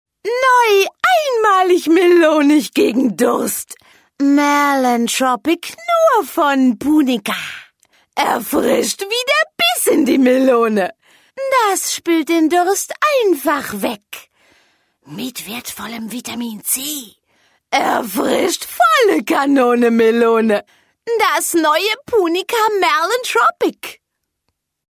Kein Dialekt
Sprechprobe: Sonstiges (Muttersprache):
female voice over talent german